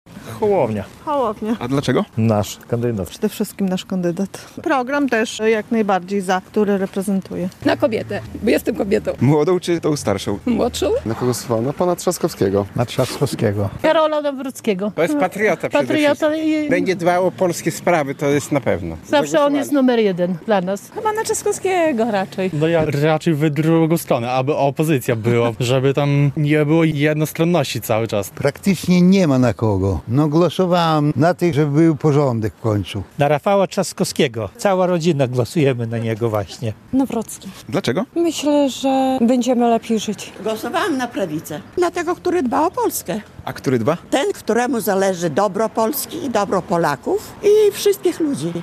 Na kogo głosowali mieszkańcy Hajnówki? - relacja